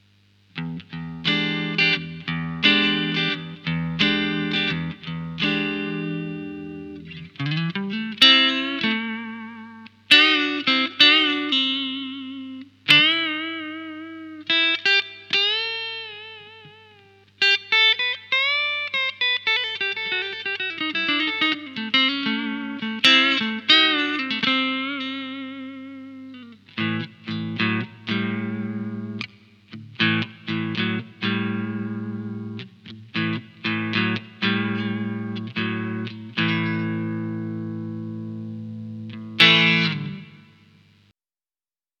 Bon allez, pour terminer en beauté, voici les samples en clean.
Le preamp 3 est le 9001
ComparatifPreamp-Clean-Preamp3.mp3